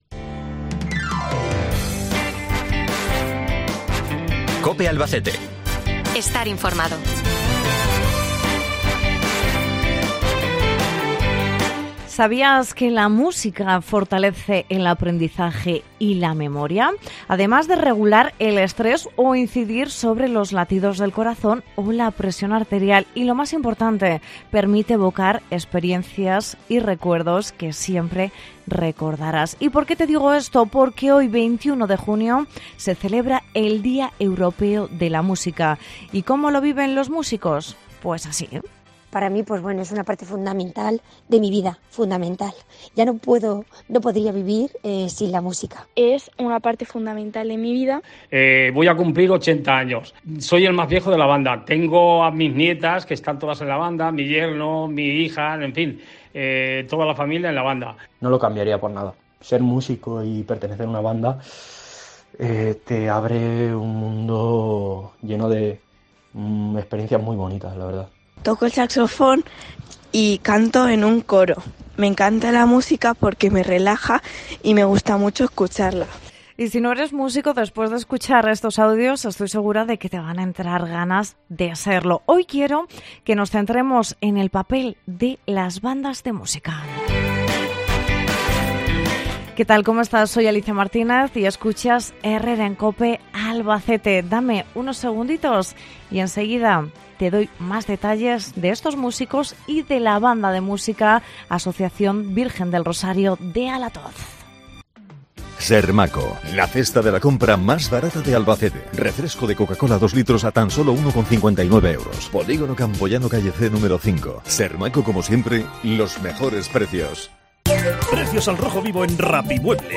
En el día Europeo de la Música con la banda musical de la Aso. Virgen de los Llanos de Alatoz